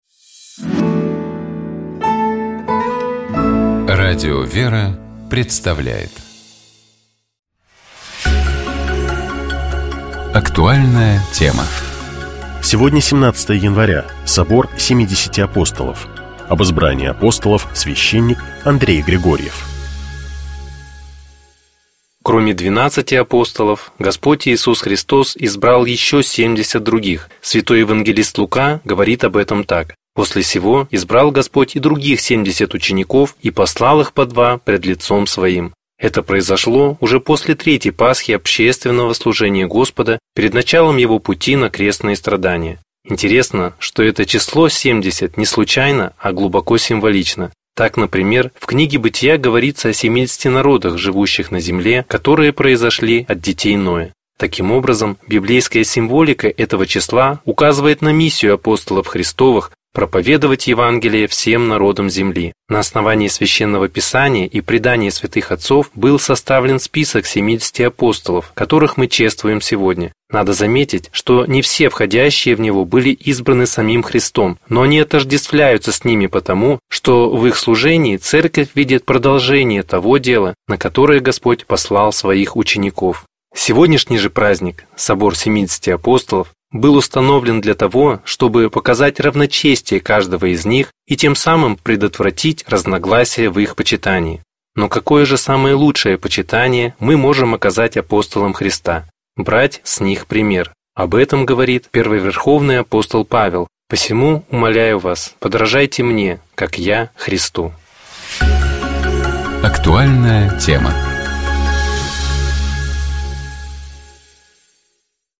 Об избрании апостолов, — священник